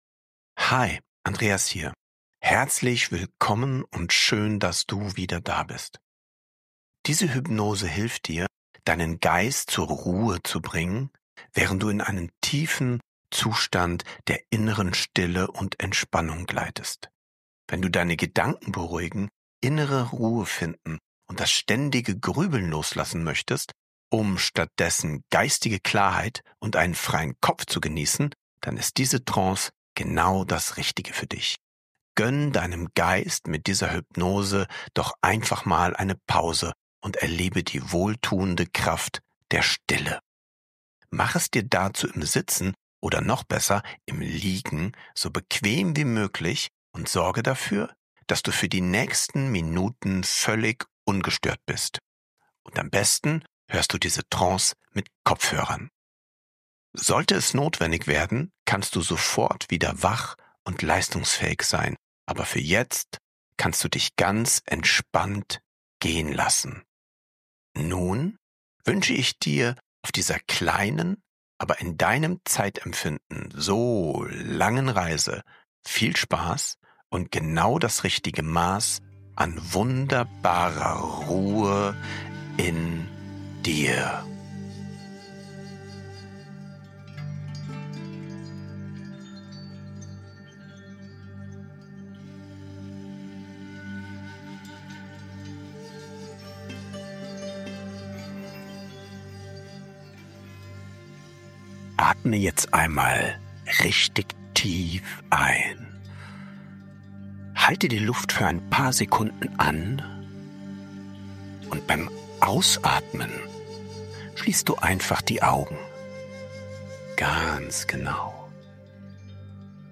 INNERE STILLE – TIEFSCHLAF HYPNOSE – Ruhe im Kopf finden & tief entspannen ~ Happiness Mindset Podcast